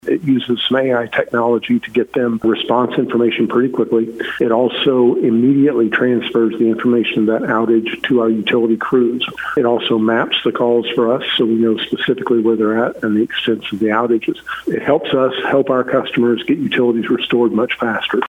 Farmington city officials encourage residents to use the new Hometown Grid Reporting system for power outages. City Administrator Greg Beavers explains how the system works and provides the reporting number.